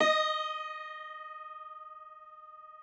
53r-pno15-D3.aif